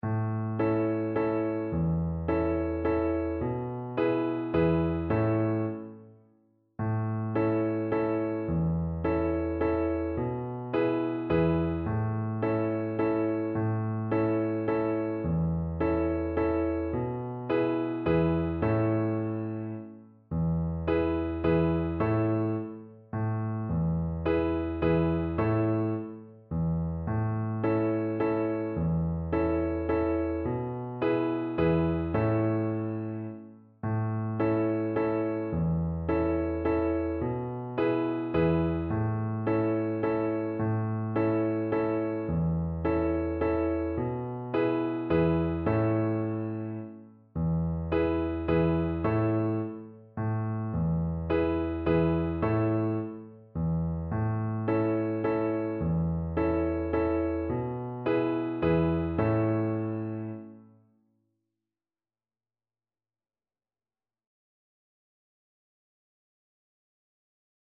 Play (or use space bar on your keyboard) Pause Music Playalong - Piano Accompaniment Playalong Band Accompaniment not yet available transpose reset tempo print settings full screen
Violin
A major (Sounding Pitch) (View more A major Music for Violin )
3/4 (View more 3/4 Music)
Steady one in a bar .=c.50
E5-F#6
Beginners Level: Recommended for Beginners
Traditional (View more Traditional Violin Music)